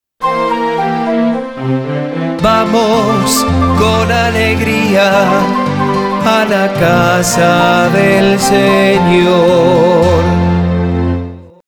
Canto litúrgico Liturgia Noticias